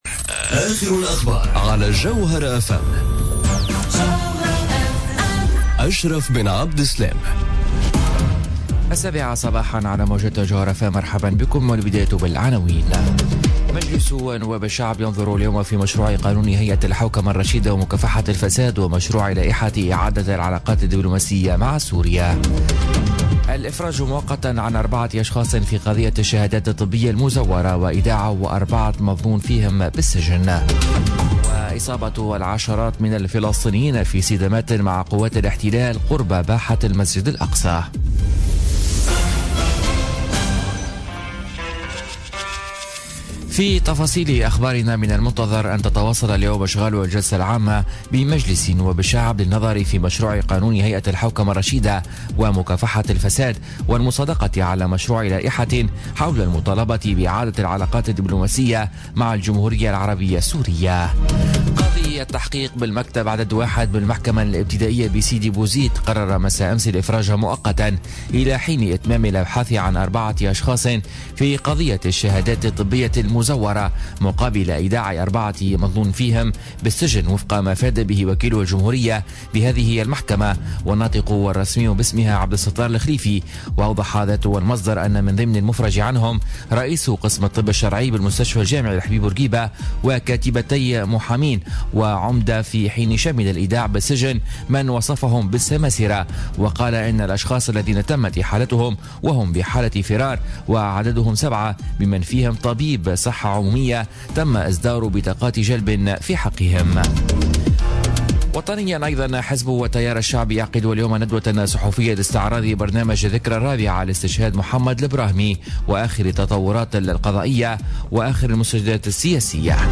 نشرة أخبار السابعة صباحا ليوم الإربعاء 19 جويلية 2017